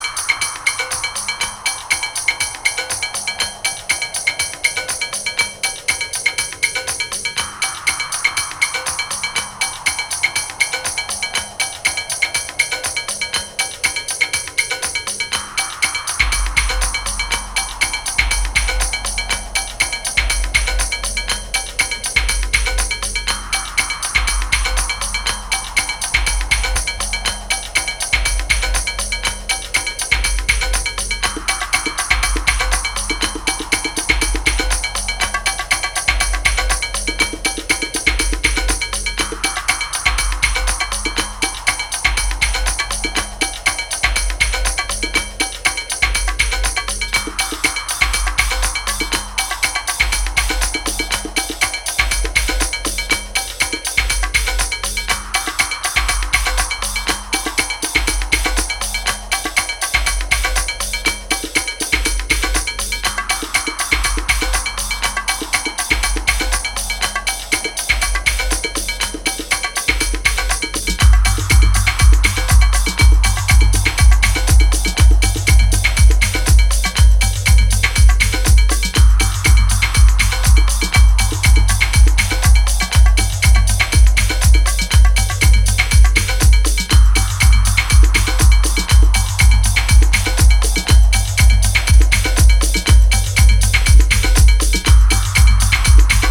PERCUSSION BONUS
ジャンル(スタイル) DEEP HOUSE